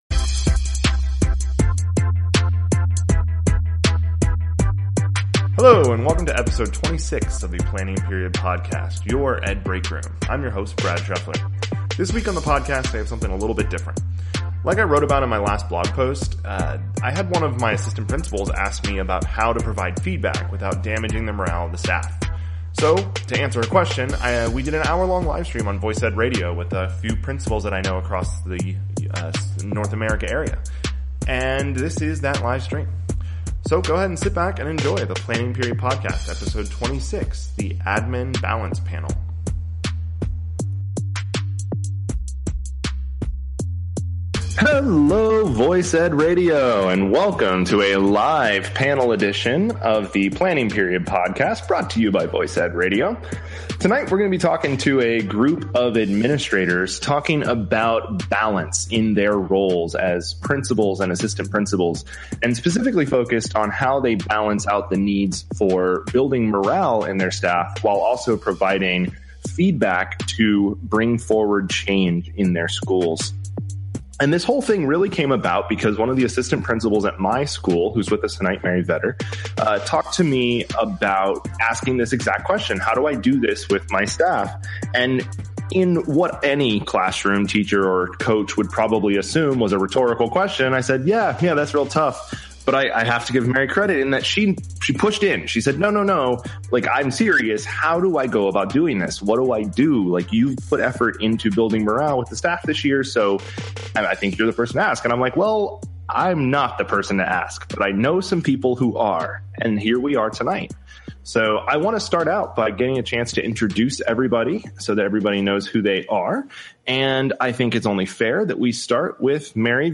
Admin Balance Panel – Episode 26